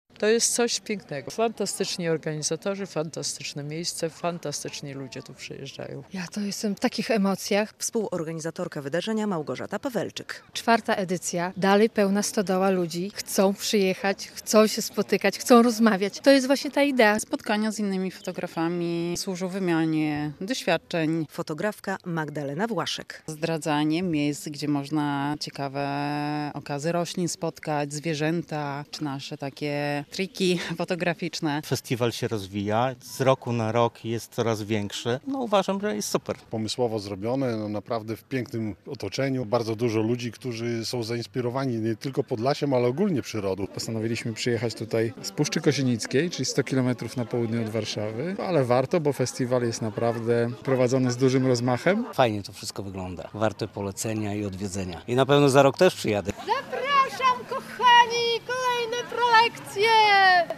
IV Niezwykłe Spotkania Fotograficzne „Zbliżenia" - relacja